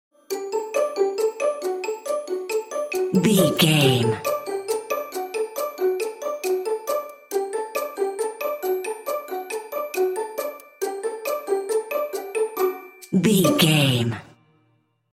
Ionian/Major
bouncy
cheerful/happy
lively
playful
strings
percussion